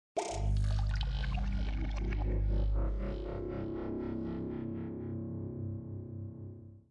描述：由各种合成器制成的变压器的声音